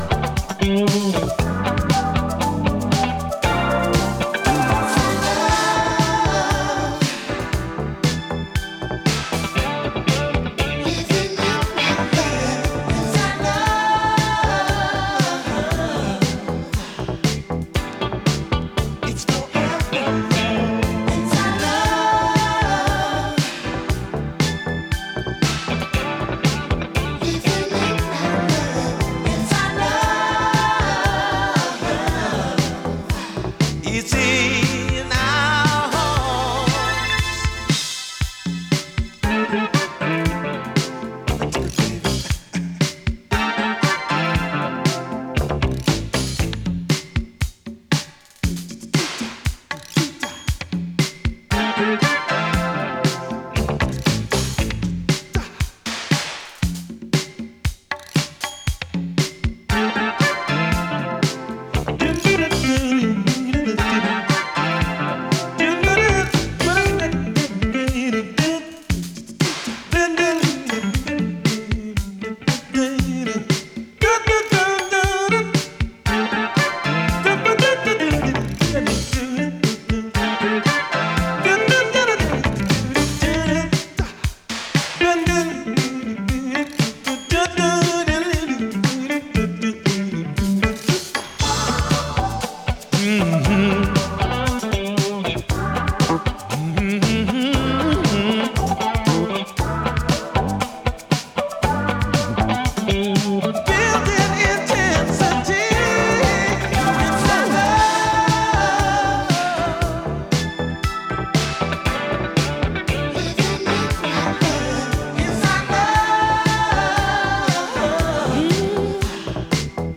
切なく込み上げる哀愁アーバン・ダンサー！